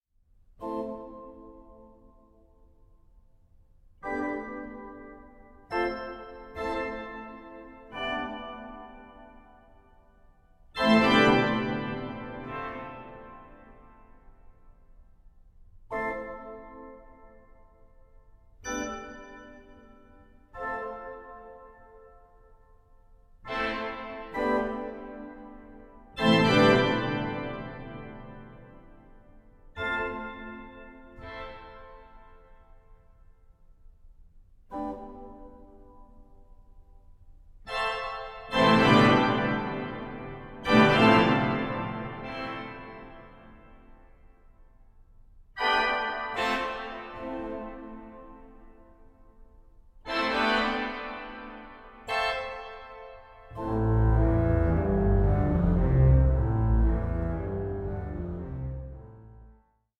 organist and keyboard player